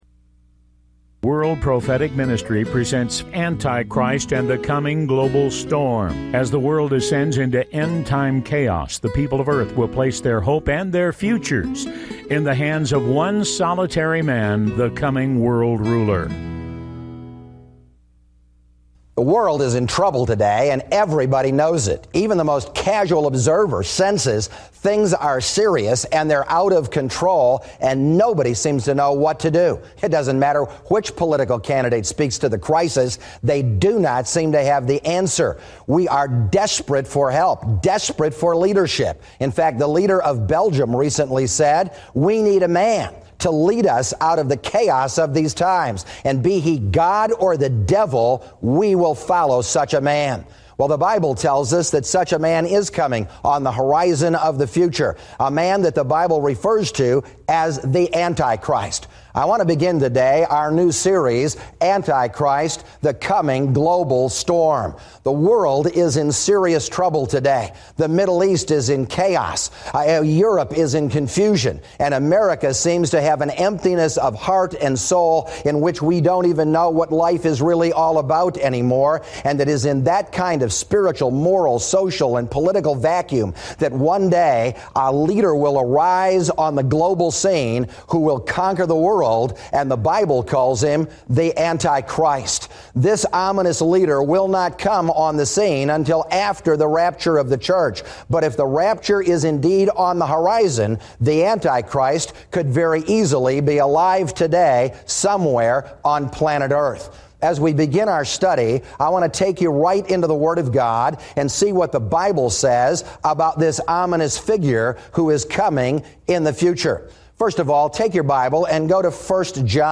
Talk Show Episode, Audio Podcast, The King Is Coming and AntiChrist The Coming Global Storm on , show guests , about AntiChrist The Coming Global Storm, categorized as Education,Health & Lifestyle,History,Love & Relationships,Philosophy,Religion,Christianity,Self Help,Society and Culture